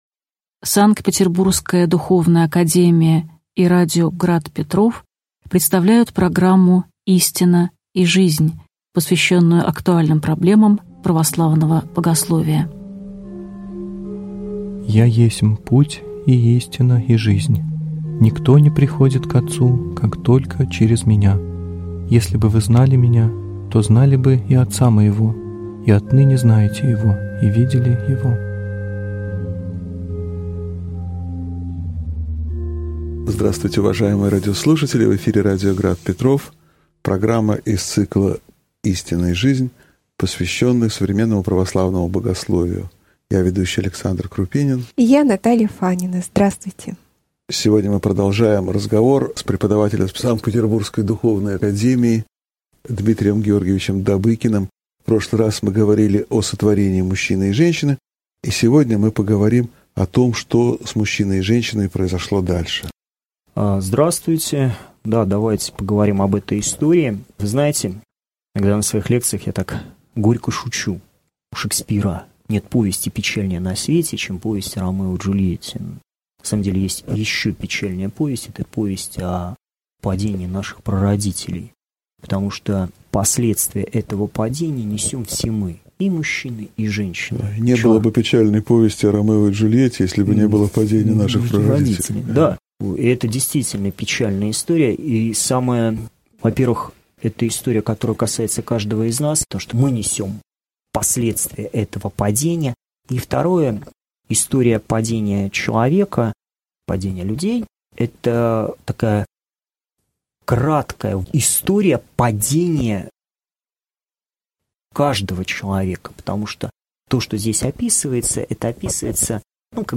Аудиокнига Мужчина и женщина в Священном Писании (часть 2) | Библиотека аудиокниг